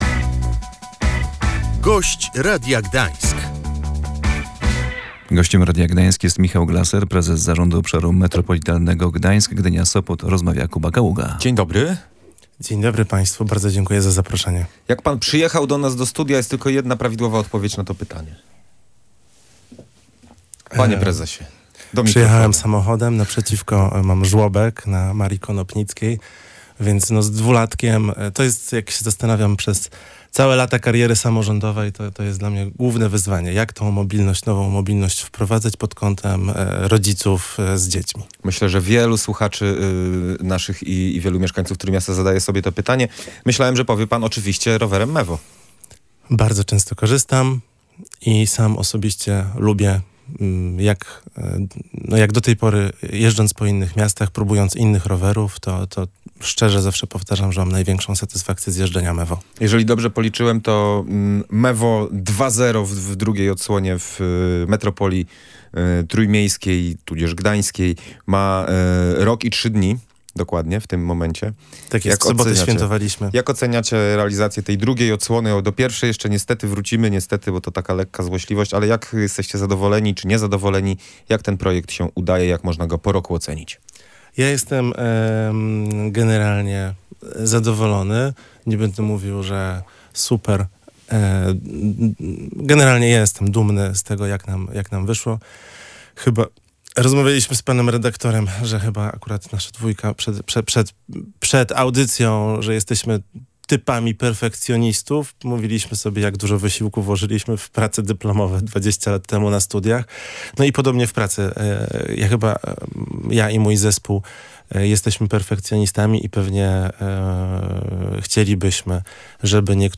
Między innymi o systemie rowerów metropolitalnych Mevo 2.0, który funkcjonuje w 16 gminach na Pomorzu, mówił na antenie Radia Gdańsk Michał Glaser, prezes zarządu Obszaru Metropolitarnego Gdańsk-Gdynia-Sopot.